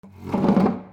椅子を引く
/ J｜フォーリー(布ずれ・動作) / J-22 ｜椅子
樹脂床 R26mix